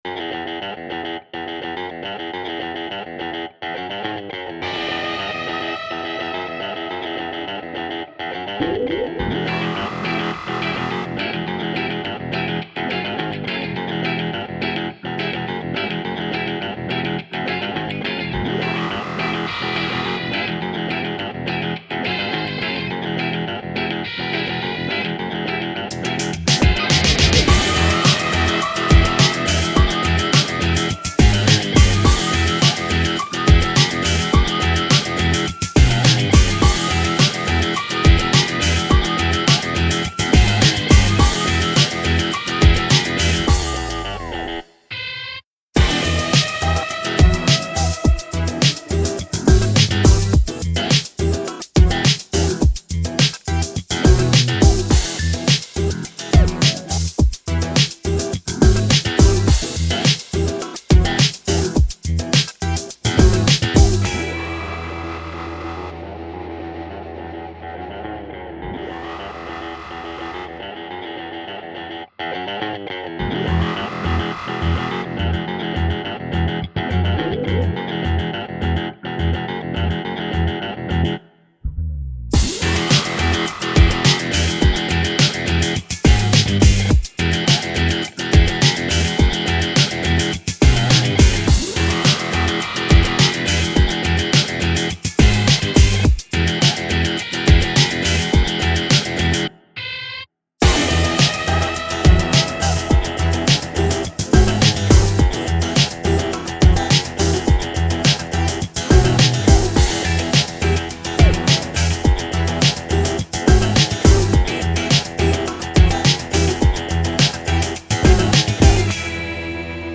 tema dizi müziği, heyecan aksiyon enerjik fon müziği.